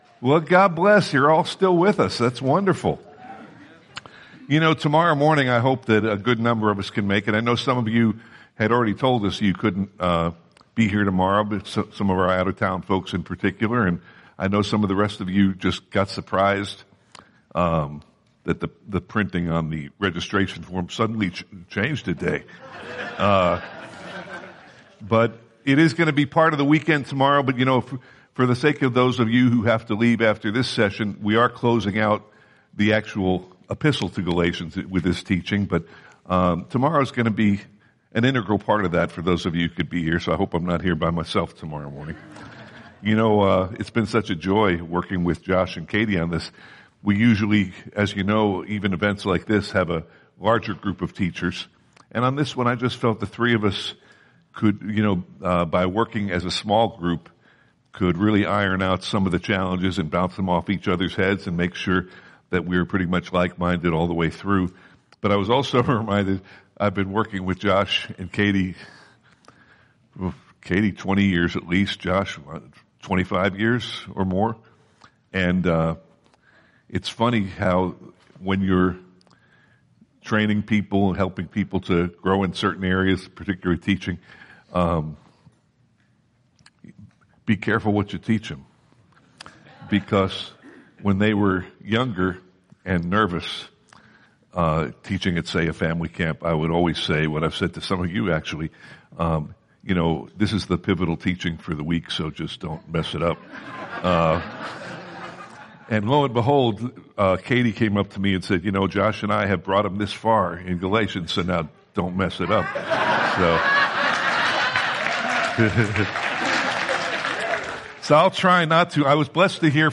Part 9 of 10 in a series of verse-by-verse teachings on the book of Galatians, with an introduction on the gospel as revealed in Romans and a closing on growing in grace.